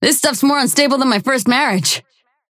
Holliday voice line